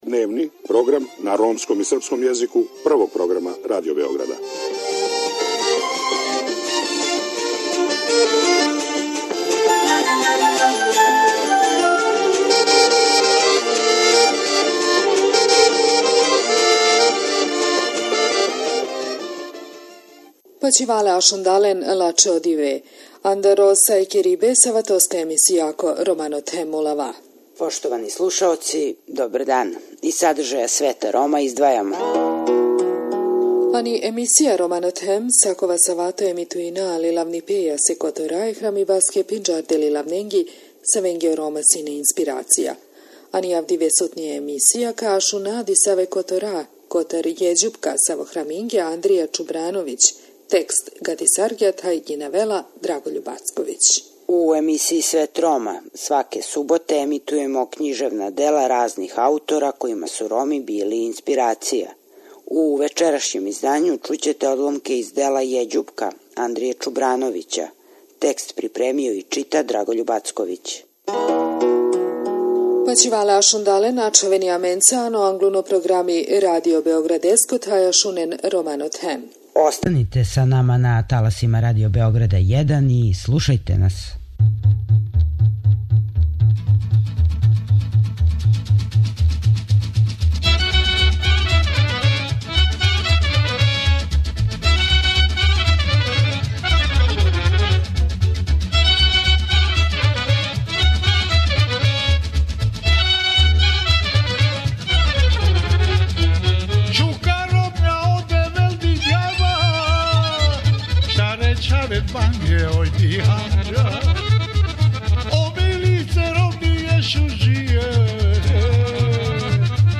У емисији Свет Рома, сваке суботе емитујемо књижевна дела разних аутора којима су Роми били инспирација.